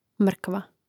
mȑkva mrkva